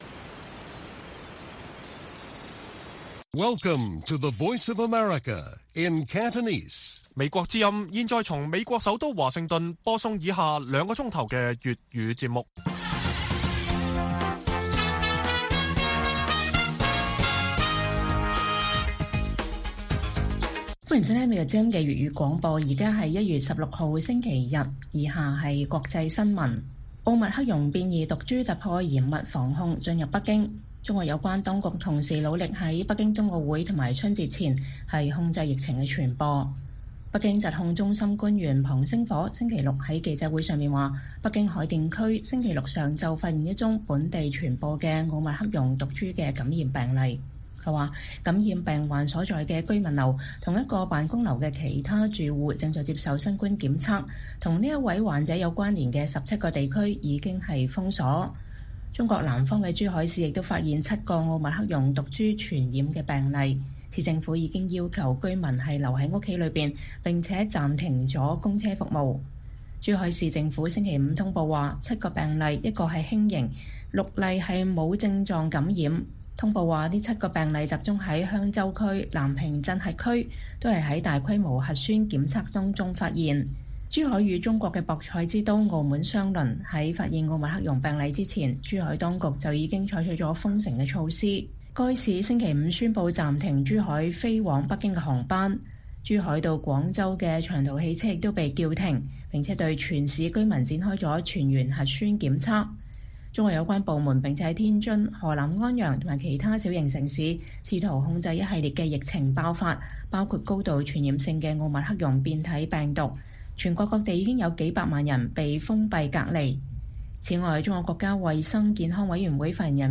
粵語新聞 晚上9-10點：奧密克戎毒株突破嚴密防控進入北京